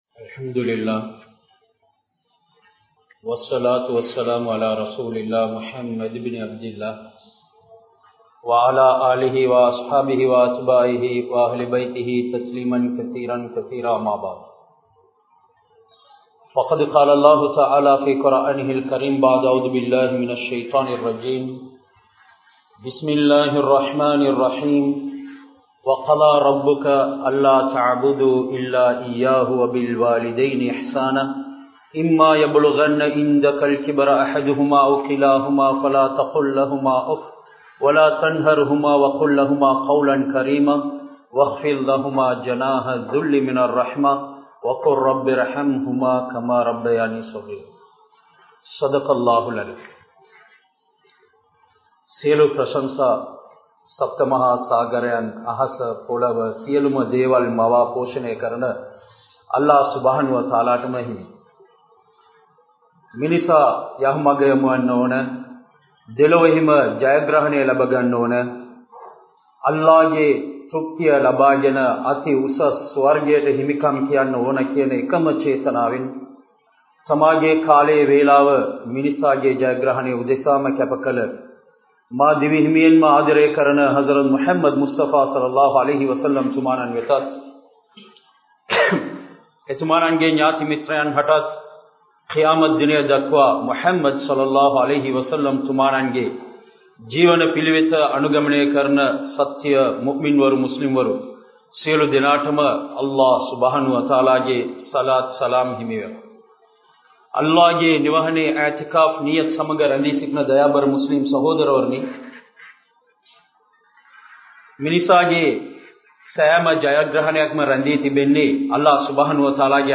Petroarhalinaal Suvarkaththai Adaiungal (පෙට්රෝර්කළිනාල් සුවර්ක්කත්තෛ අඩෛයුංගළ්) | Audio Bayans | All Ceylon Muslim Youth Community | Addalaichenai